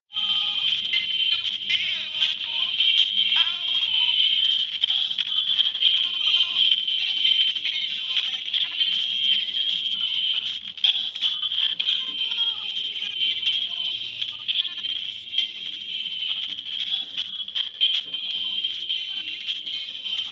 PDM captured data from mic is distorted - Nordic Q&A - Nordic DevZone - Nordic DevZone
Hello, we are capturing sound with a PDM mic, and sending it to a codec through I2S.